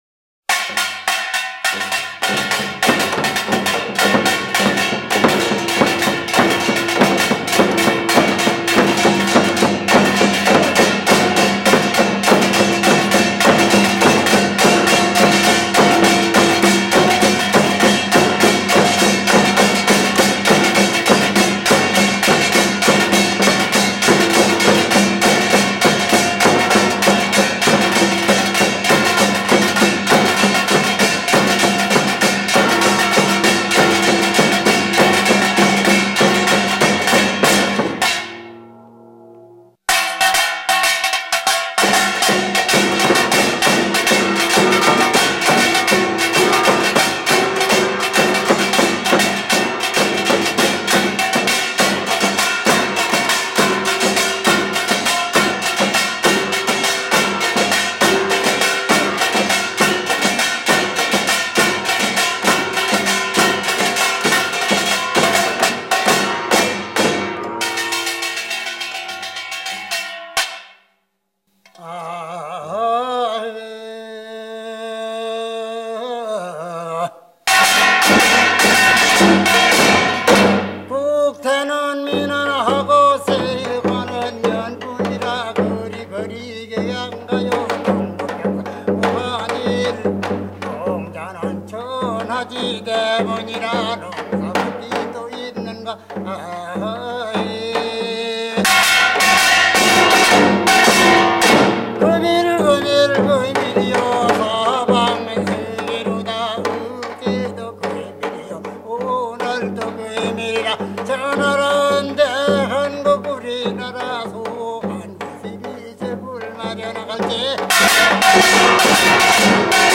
1 告祀歌と遊び歌 #1